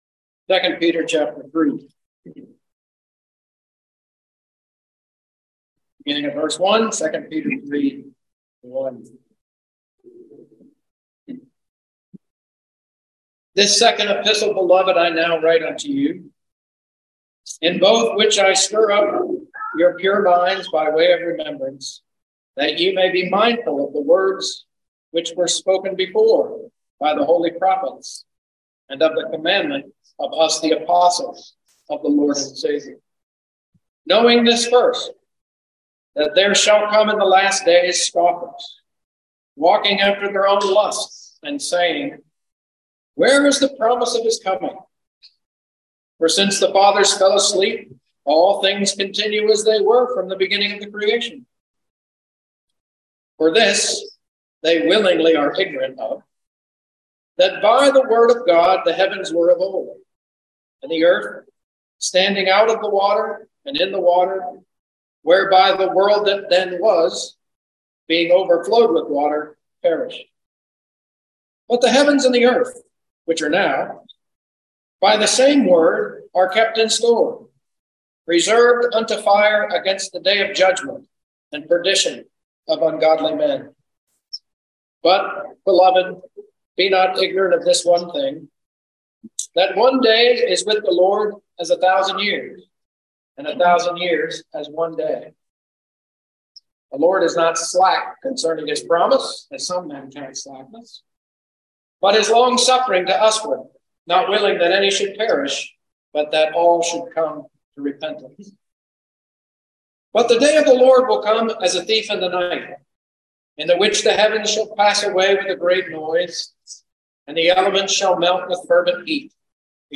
Bible Reading – 2 Peter 3 – (Cooroy Conference 2023)